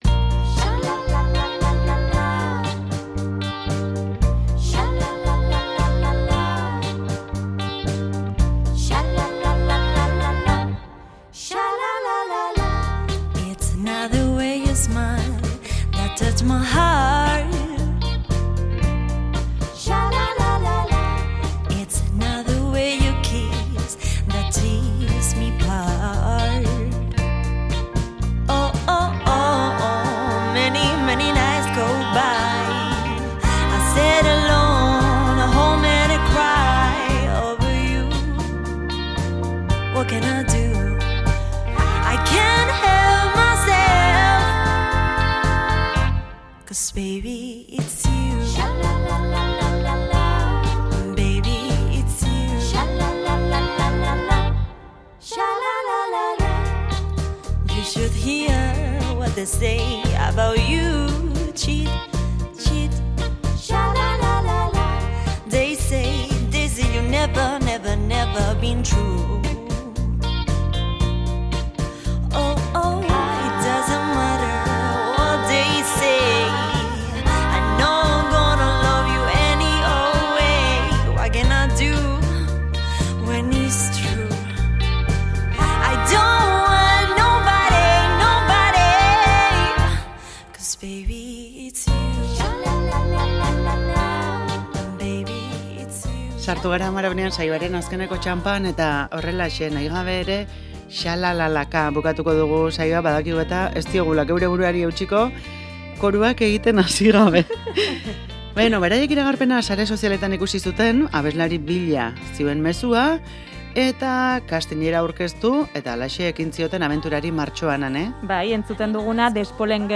elkarrizketatu ditugu Amarauna saioan